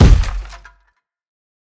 TM88 BlockKick.wav